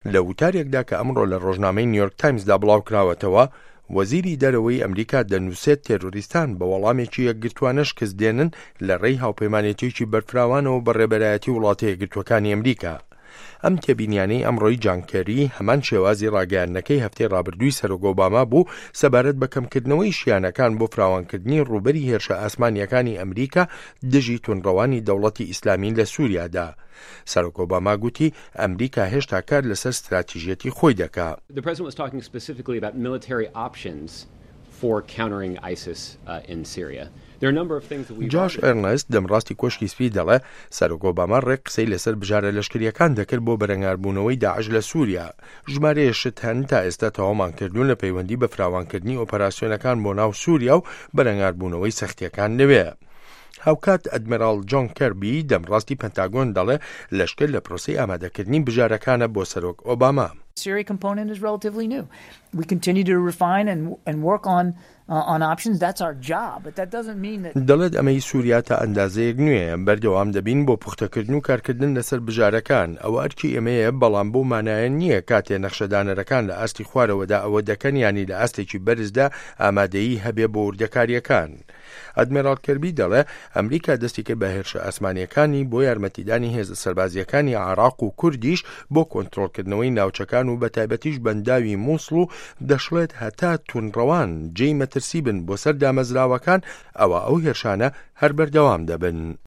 ڕاپـۆرتی ئه‌مه‌ریکا و ڕووبه‌ڕووبوونه‌وه‌ی داعش